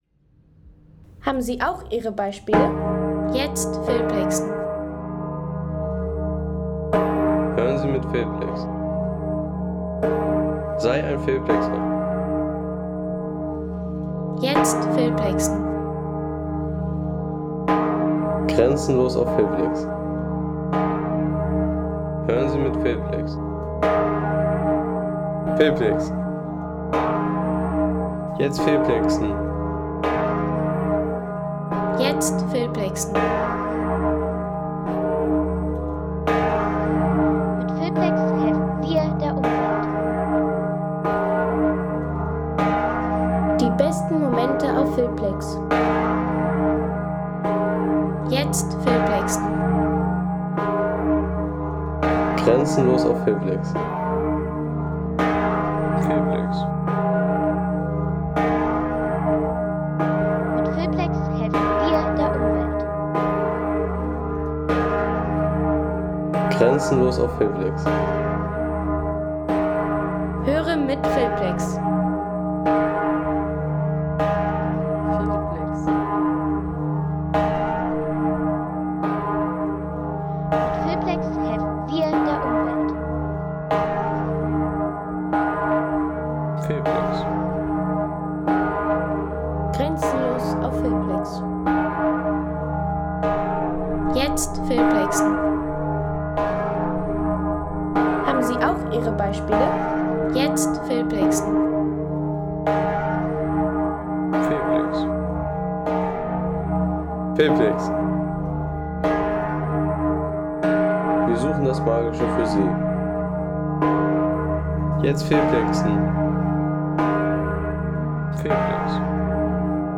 Ein Glockensound, der weit mehr trägt als nur Klang
Feierliches Geläut der Friedensglocke von Mösern mit alpiner Resonanz und symbolischer Ruhe.
Feierliche Glockenatmosphäre der Friedensglocke von Mösern mit alpiner Ruhe, weiter Resonanz und symbolischer Tiefe.